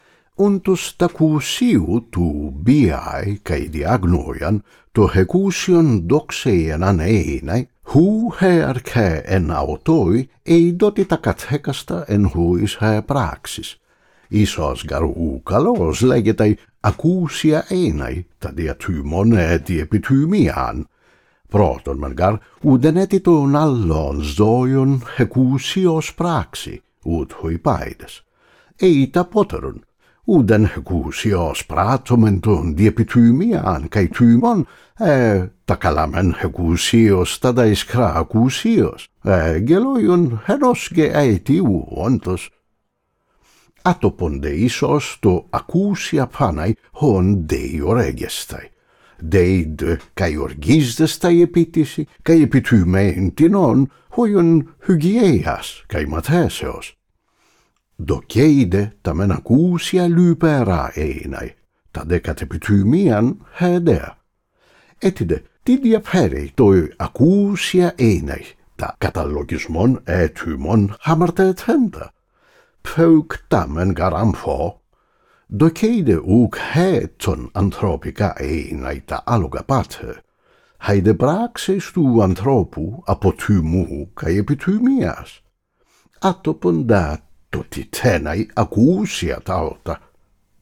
Nicomachean Ethics book 3 • Aristotle • spoken Ancient Greek
Unabridged
You can listen to chapter 03 (03.03) of “Nicomachean Ethics, book 03”, an audio/video sample of the present audiobook.